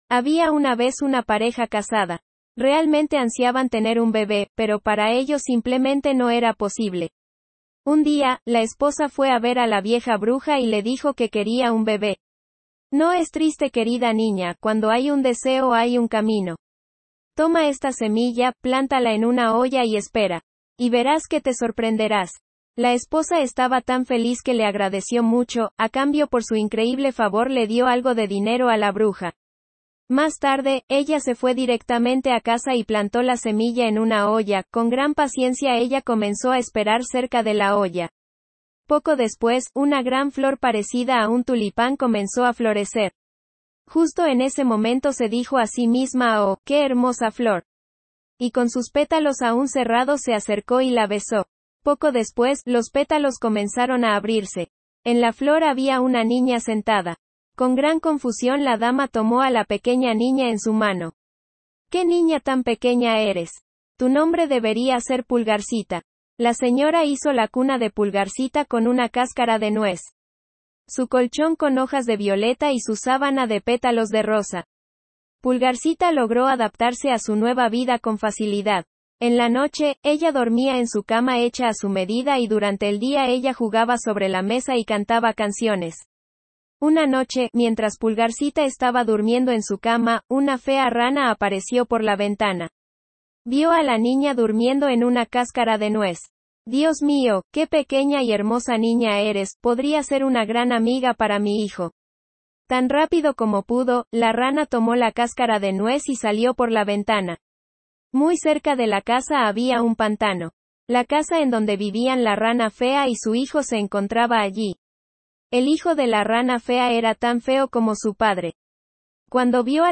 ¡Aquí tienes el audio cuento!